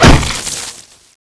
hit_world1.wav